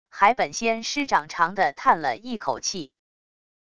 海本仙师长长地叹了一口气wav音频生成系统WAV Audio Player